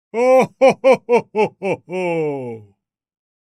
Santa Claus Laugh Sound Effect
Iconic and recognizable ho ho ho laugh of Santa Claus or Saint Nicholas delivering gifts. Santa Claus’s laugh isolated without effects. Christmas sounds.
Santa-claus-laugh-sound-effect.mp3